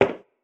inside-step-3.wav